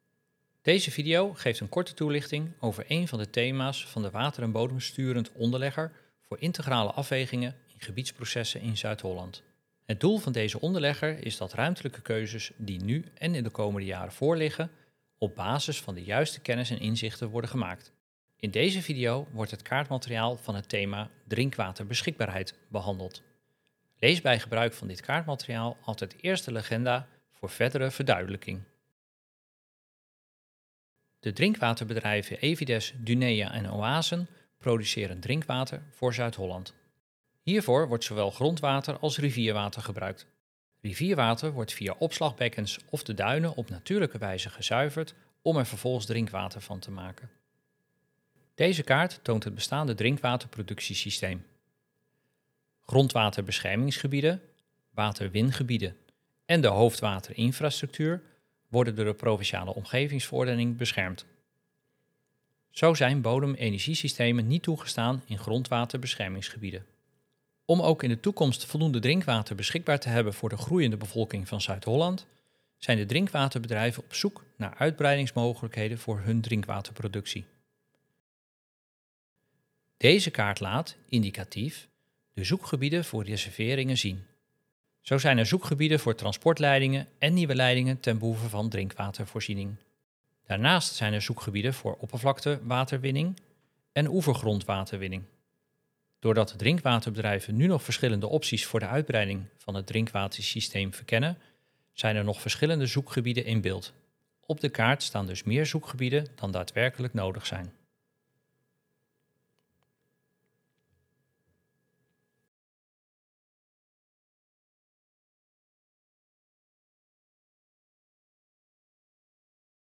provincie_zuid-holland_animatie-4_drinkwaterbeschikbaarheid_voice-over.mp3